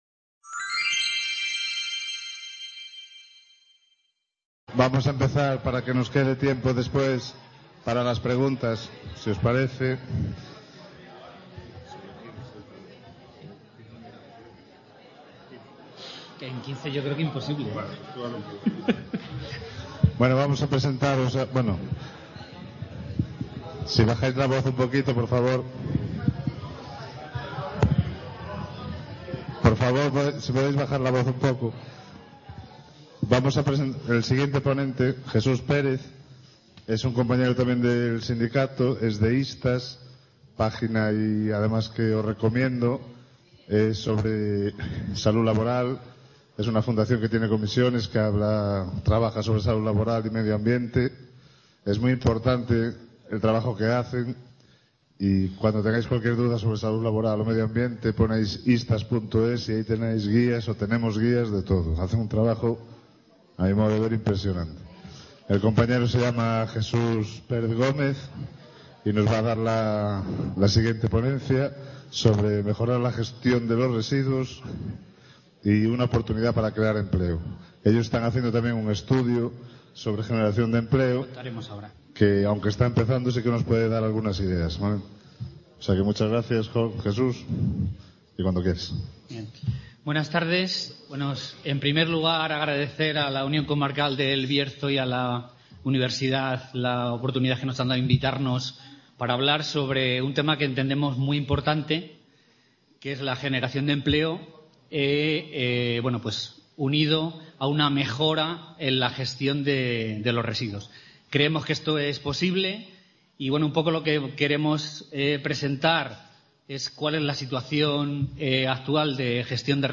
C.A. Ponferrada. II Jornadas Otra Economía es Posible: Gestión de residuos y creación de empleo.